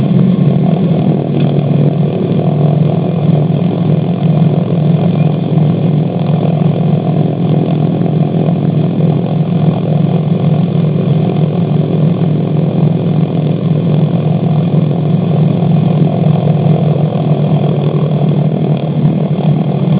DC-3 Sound Files
Taxiing on a windy day (recorded from inside the passenger cabin).